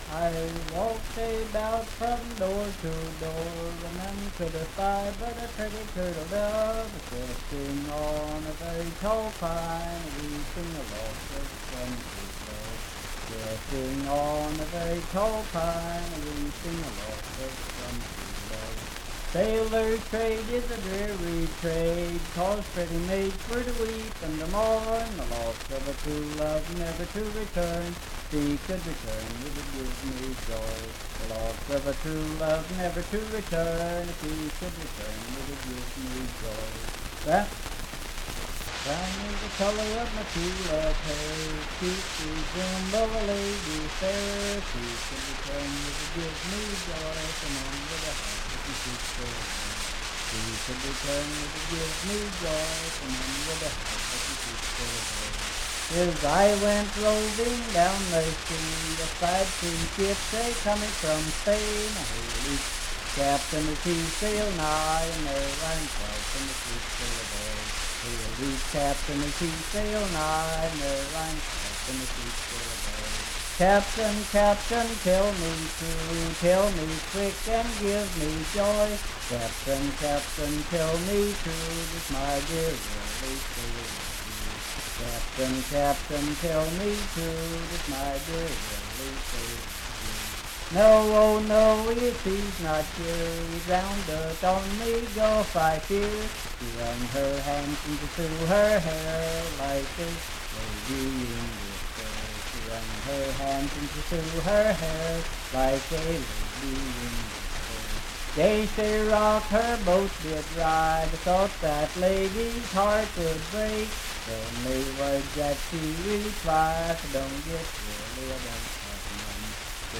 Unaccompanied vocal music
in Riverton, W.V.
Voice (sung)